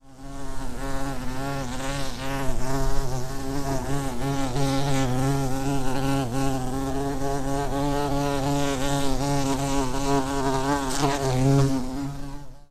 جلوه های صوتی
دانلود صدای زنبور 3 از ساعد نیوز با لینک مستقیم و کیفیت بالا